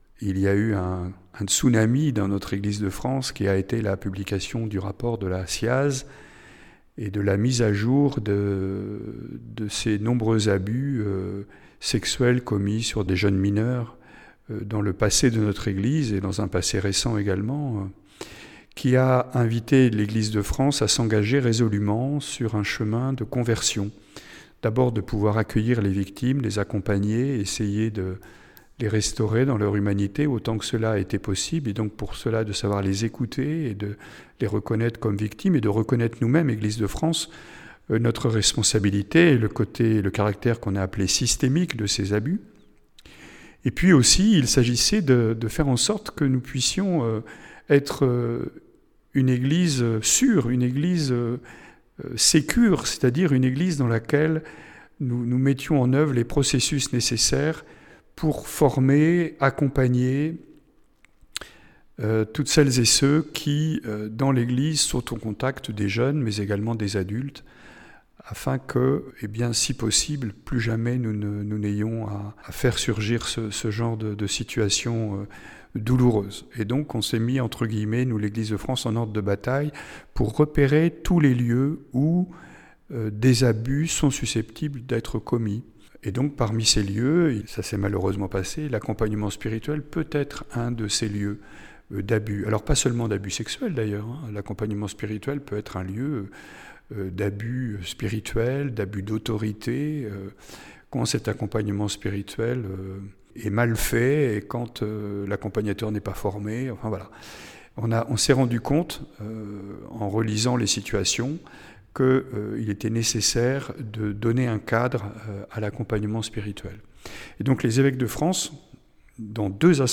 Lors de son entretien à Radio-Fidélité du 16 janvier 2026, Mgr Percerou est revenu sur la promulgation de cette charte, vous pouvez (ré)écouter l’extrait de cet entretien ci-dessous :